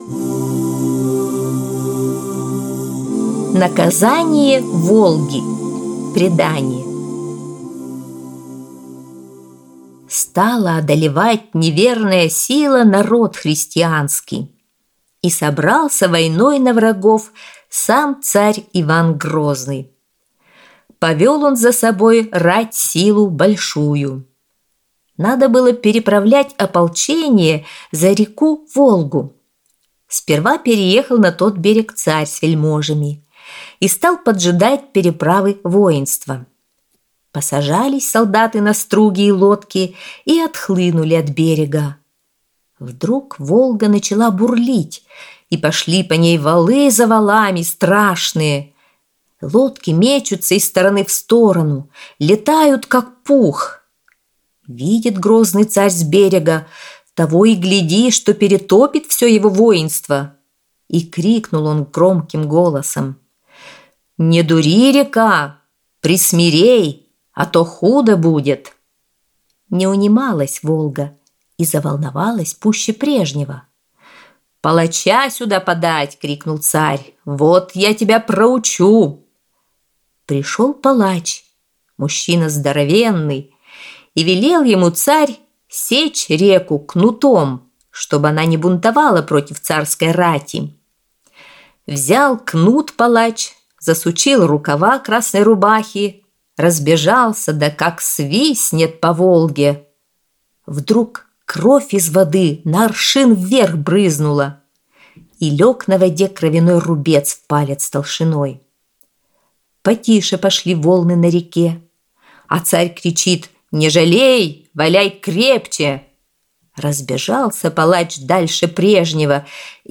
Аудиосказка «Наказание Волги»